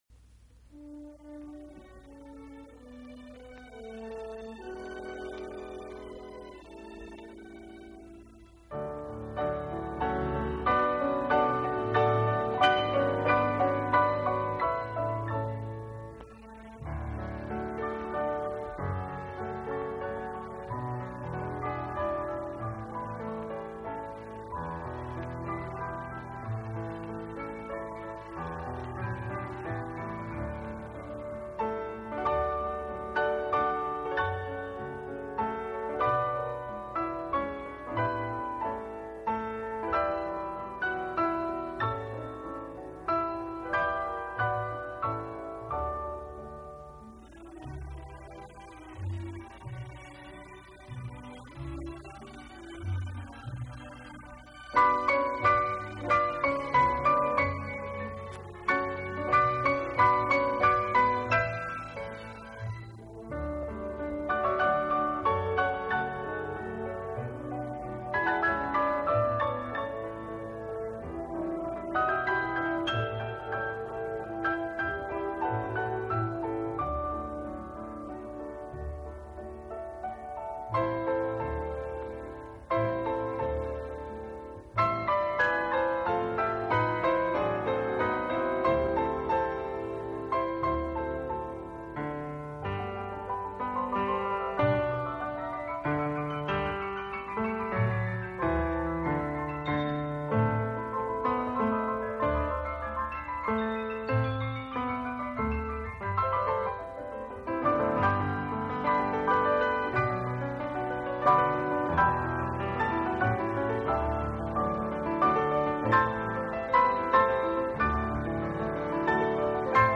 【双钢琴】